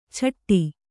♪ chaṭṭi